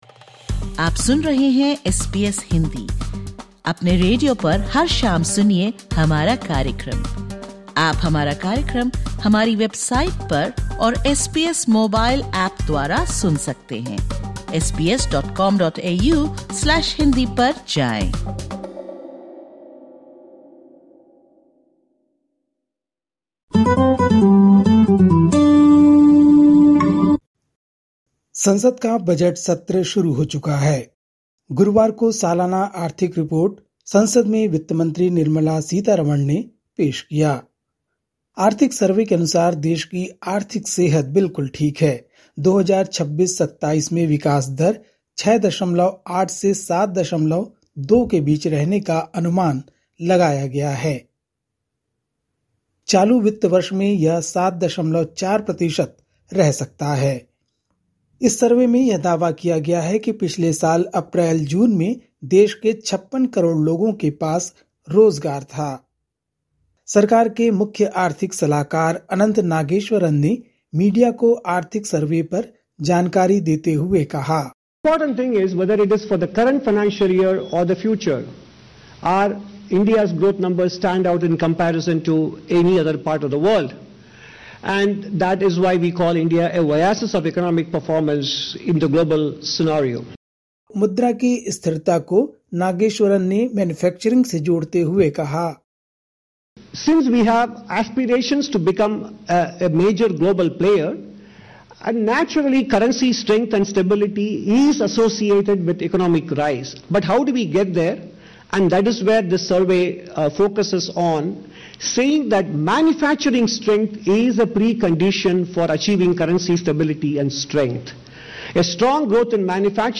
Listen to the latest SBS Hindi news from India. 30/01/2026